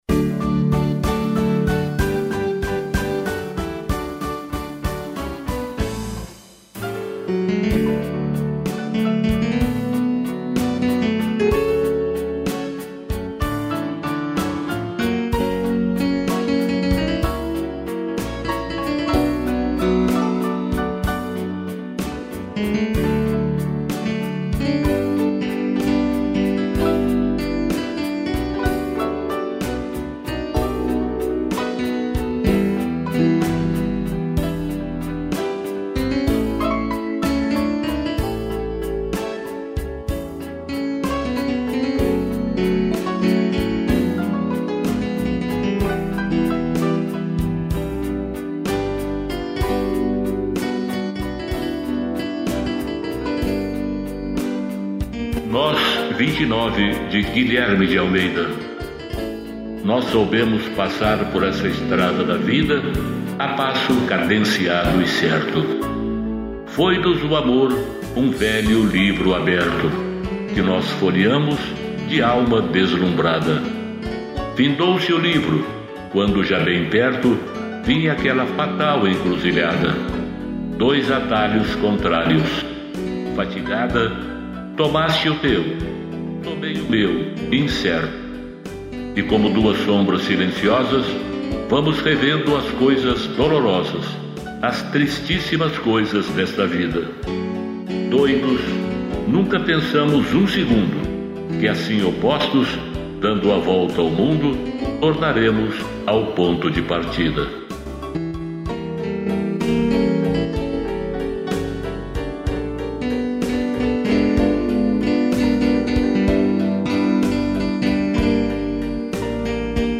piano, flauta e sax